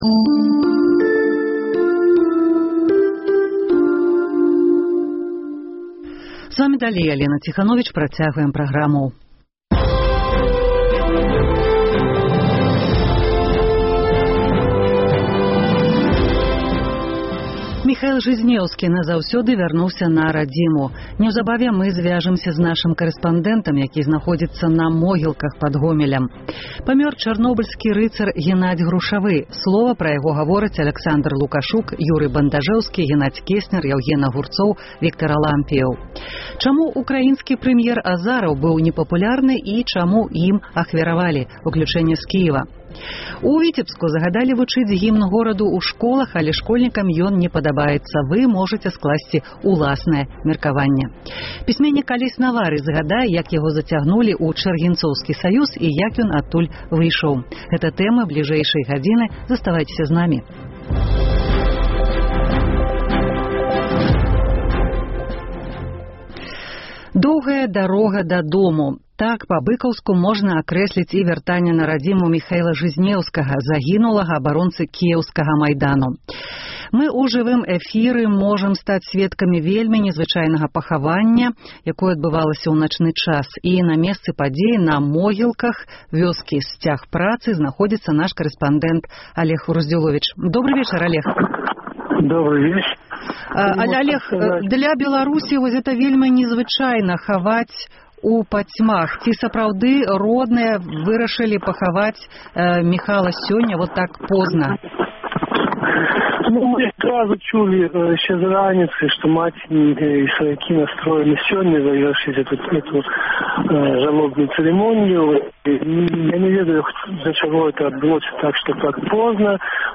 Чаму ўкраінскі прэм’ер Азараў быў непапулярны і чаму ім ахвяравалі? Уключэньне з Кіева. У Віцебску загадалі вучыць гімн гораду ў школах, але школьнікам ён не падабаецца.